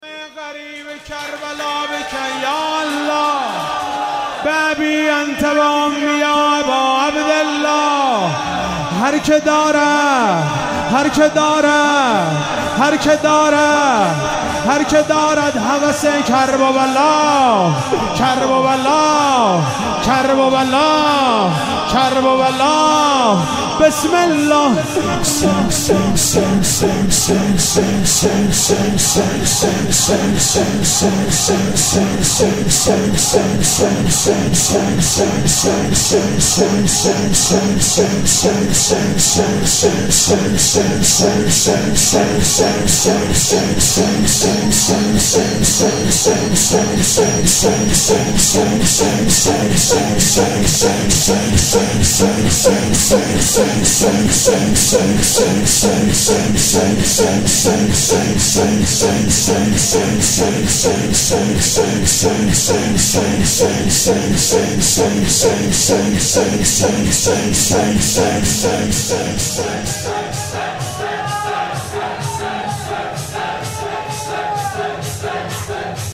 شب نوزدهم ماه رمضان98 - شور - به دم غریب کربلا بک یا الله